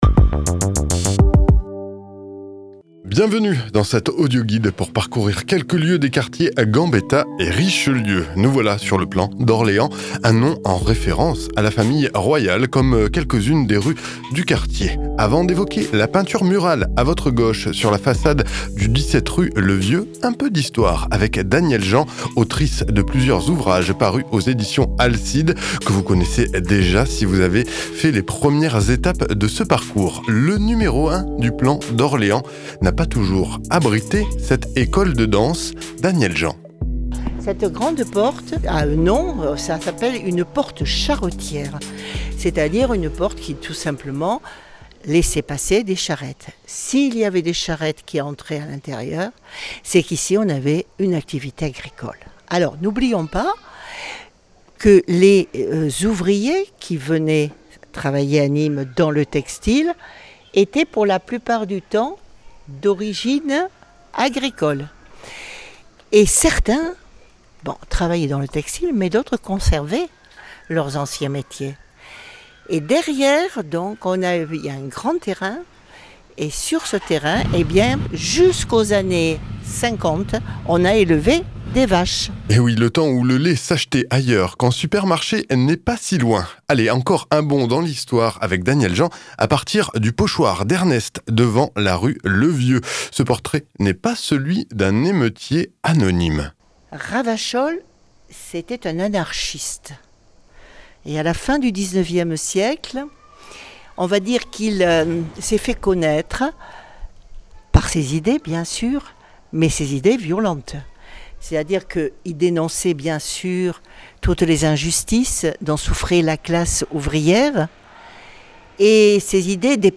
Parcours Gambetta – Journées Européennes du Patrimoine 2023 // Audioguide de Ouf!
04-audioguide-de-ouf-plan-d-orleans.mp3